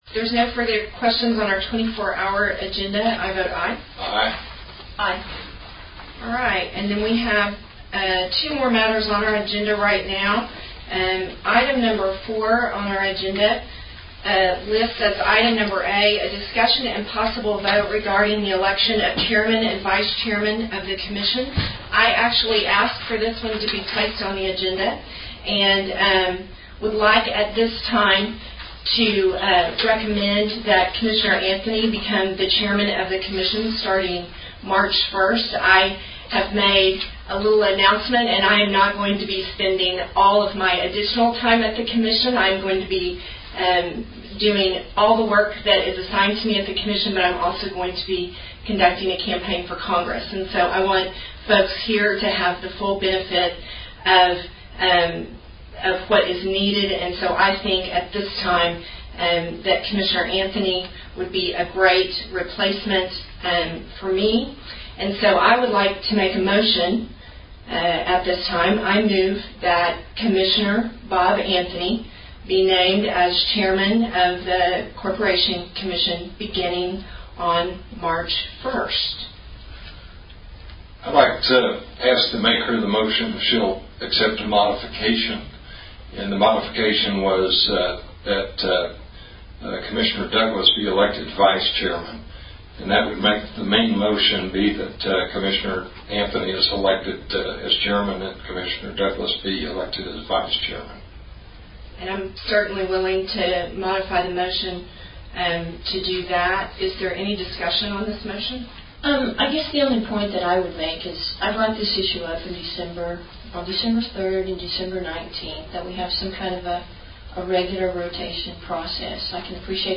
A recording of the OCC’s February 18, 2014 meeting suggests the change directly linked to Commissioner Patrice Douglas’ effort to win a seat in Congress from the Fifth District of Oklahoma.